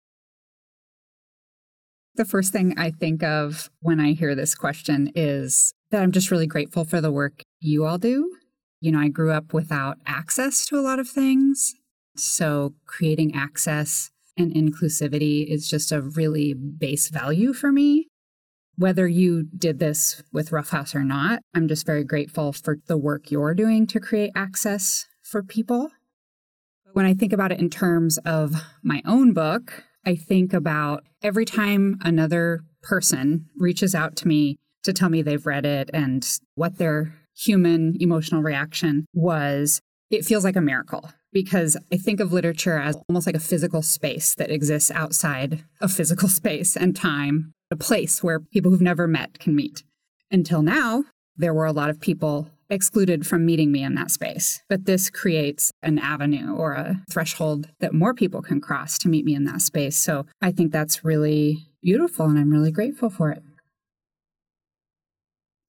recorded at the State Library of Oregon